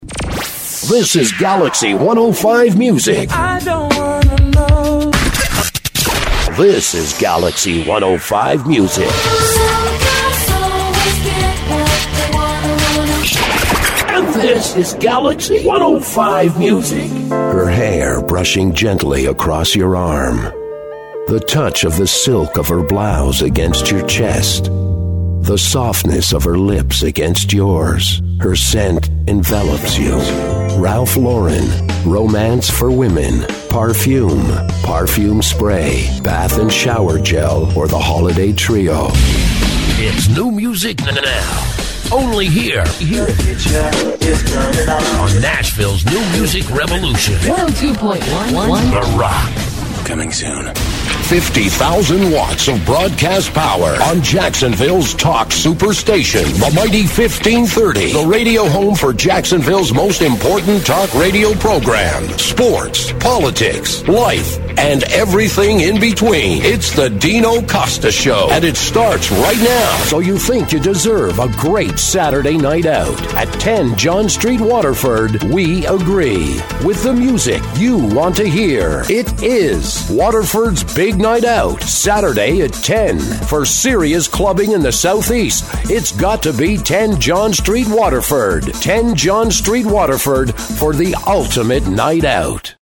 Male Voice Overs
a deep base voice but also a lot of range